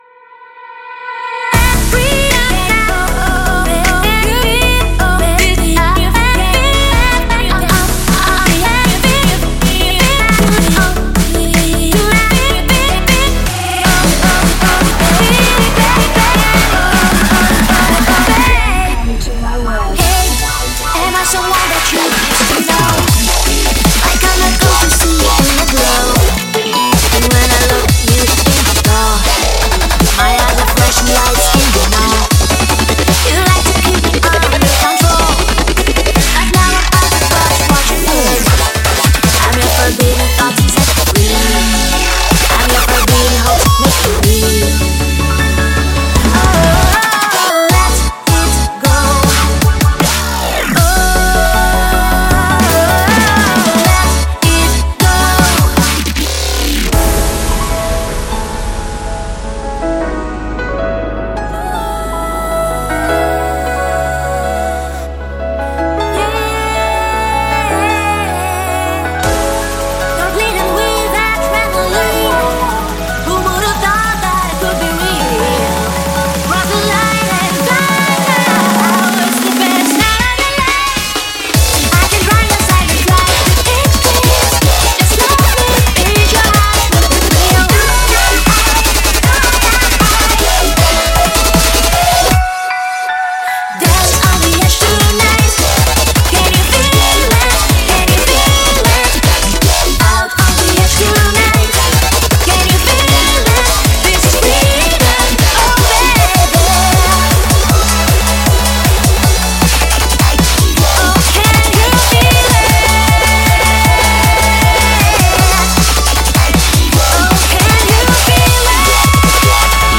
BPM156
Audio QualityMusic Cut